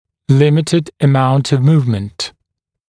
[‘lɪmɪtɪd ə’maunt əv ‘muːvmənt][‘лимитид э’маунт ов ‘му:вмэнт]ограниченный диапазон (запас) перемещения